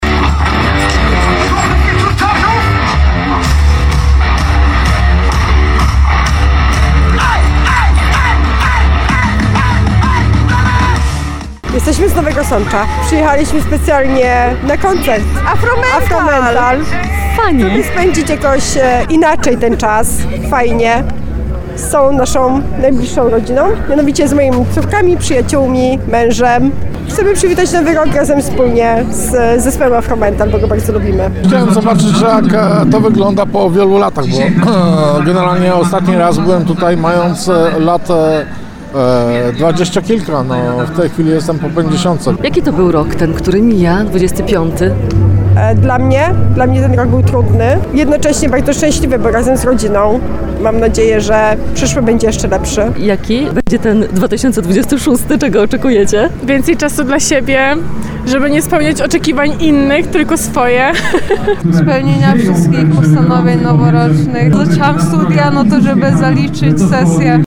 Z zespołem 'Afromental’ mieszkańcy Tarnowa powitali 2026 rok. Przy ratuszu zgromadziły się prawdziwe tłumy osób, którym nie straszny był mróz i późna pora. Hucznie żegnali stary rok i z nadzieją witali nowy.